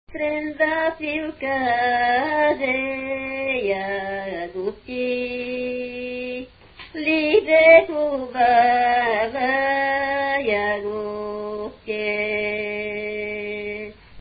музикална класификация Песен
размер Безмензурна
фактура Едногласна
начин на изпълнение Солово изпълнение на песен
битова функция На седянка
фолклорна област Средногорие
начин на записване Магнетофонна лента